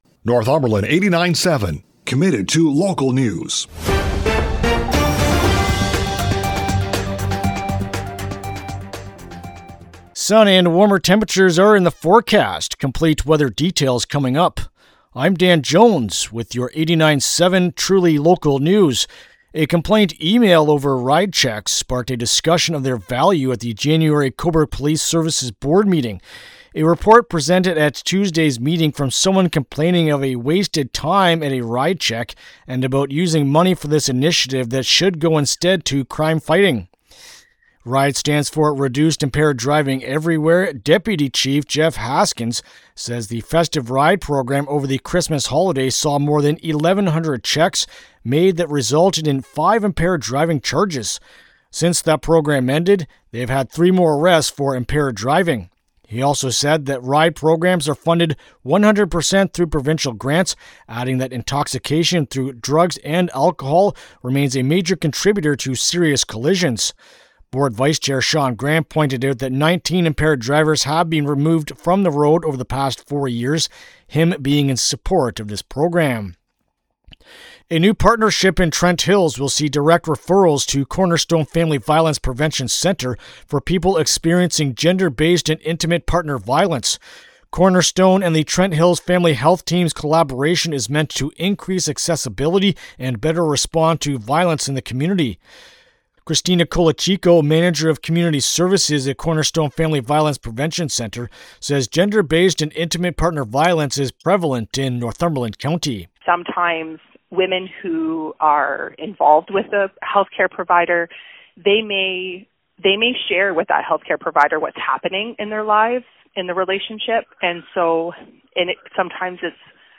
CFWN-Cobourg-On.-Thurs.-Jan-29-Morning-News.mp3